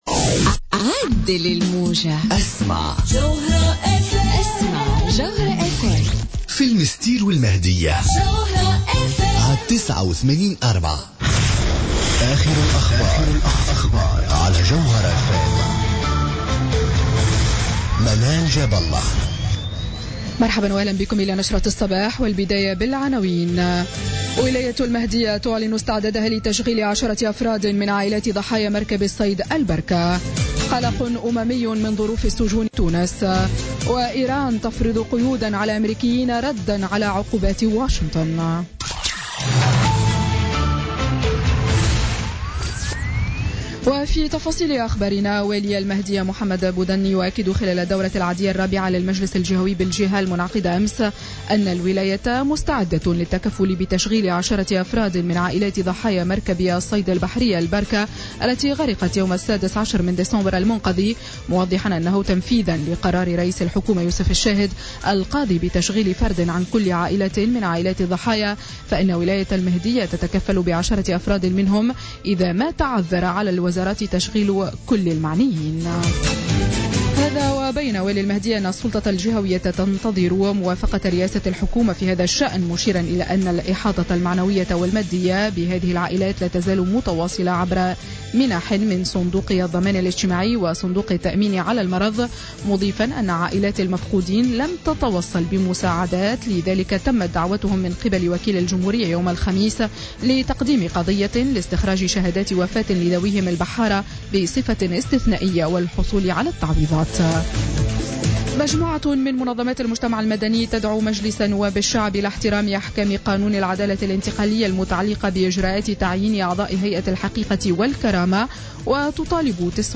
نشرة أخبار السابعة صباحا ليوم السبت 4 فيفري 2017